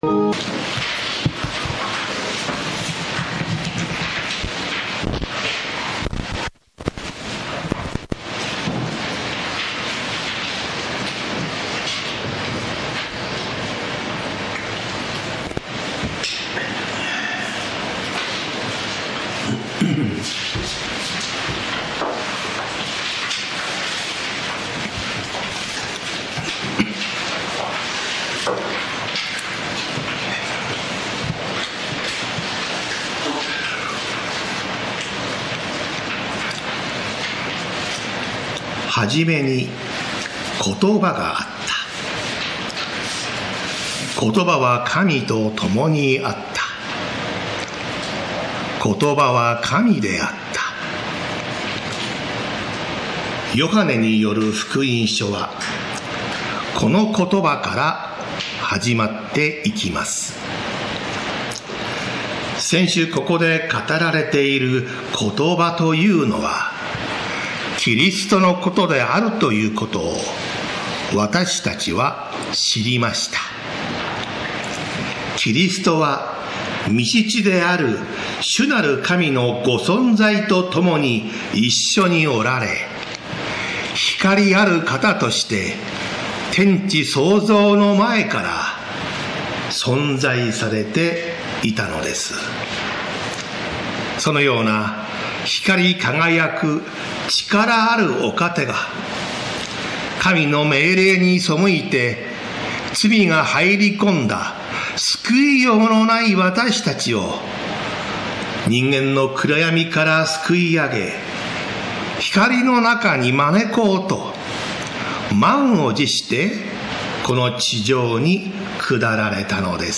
栃木県鹿沼市 宇都宮教会